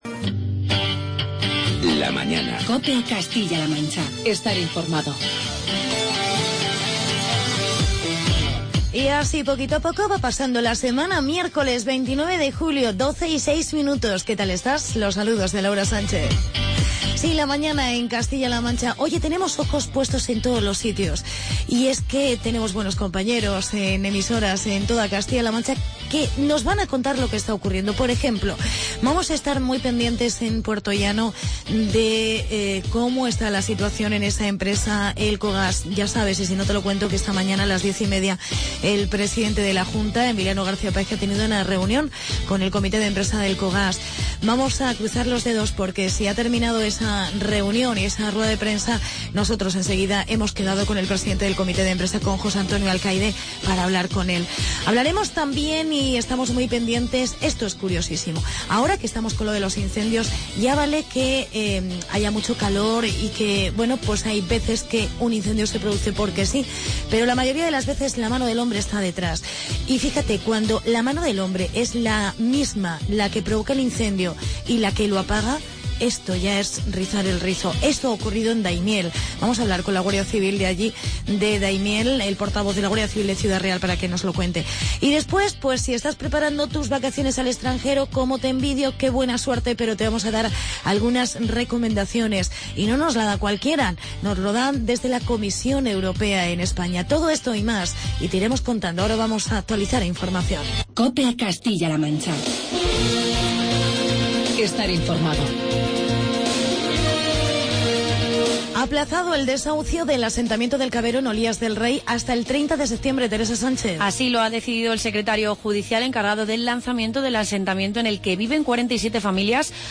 Entrevista Guardia Civil Ciudad Real.